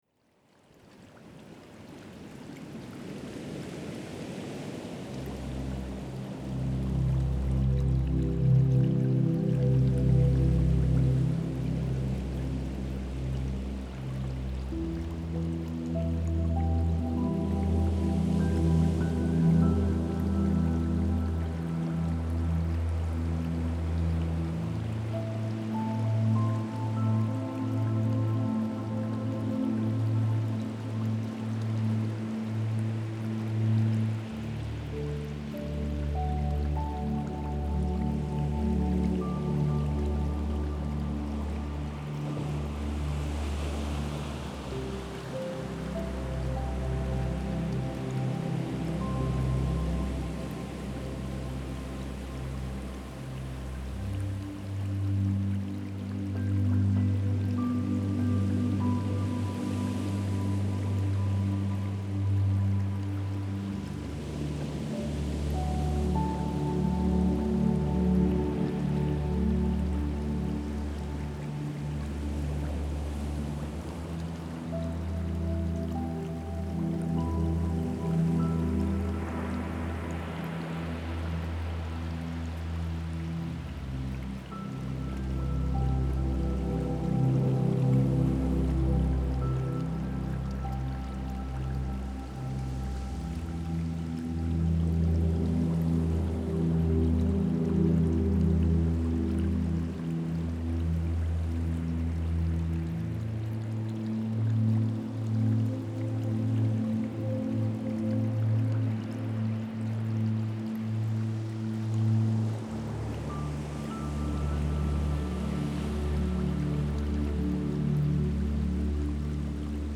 это атмосферная композиция в жанре неоклассики